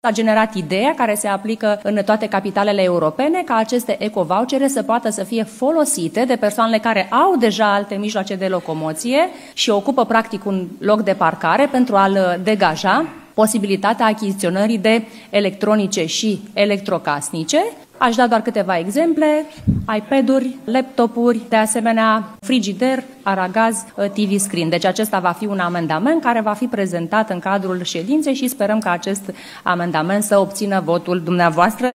Primarul general Gabriela Firea.